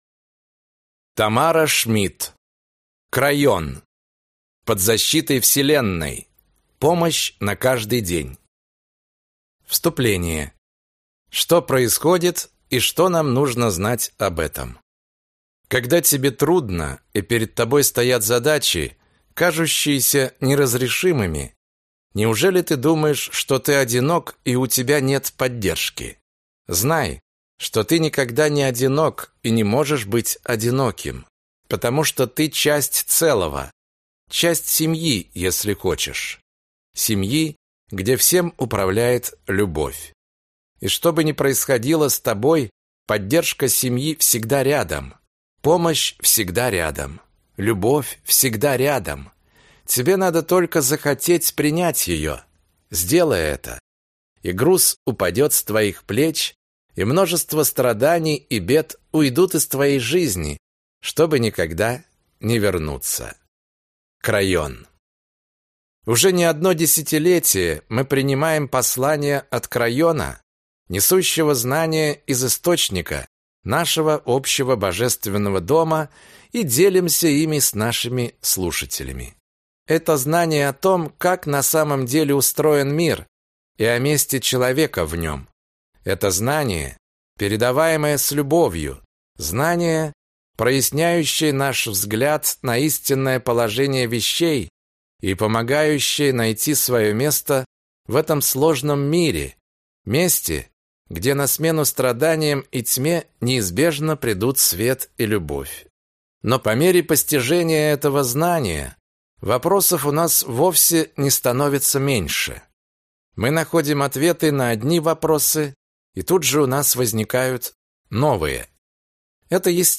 Аудиокнига Крайон. Под защитой Вселенной. Помощь на каждый день | Библиотека аудиокниг